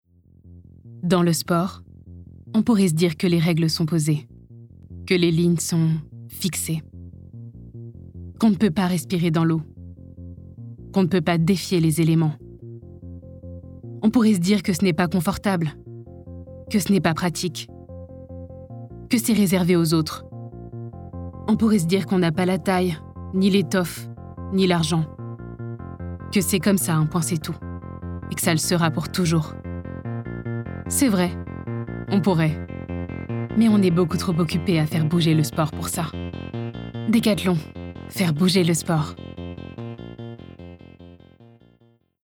Une voix qui ne triche pas, et une présence simple, chaleureuse et pleine de vie.
13 - 45 ans - Mezzo-soprano